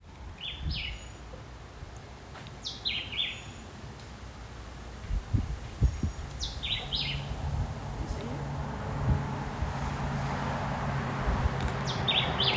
Black-whiskered Vireo
Terrebonne